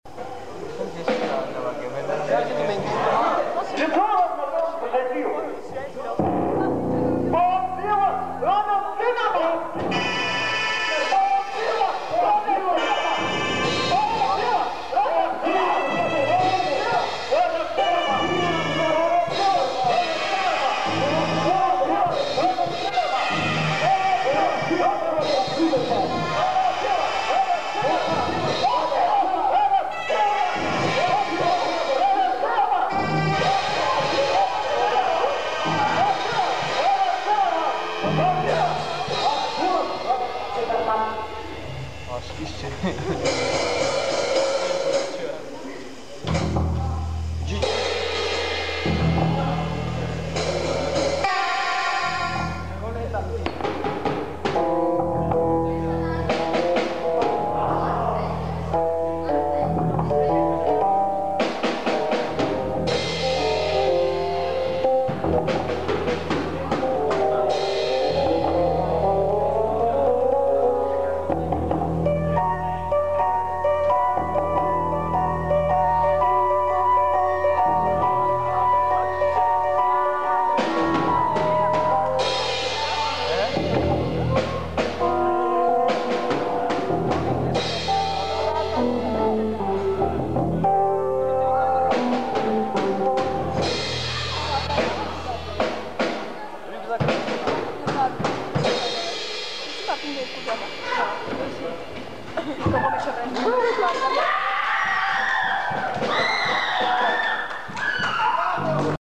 (концертник)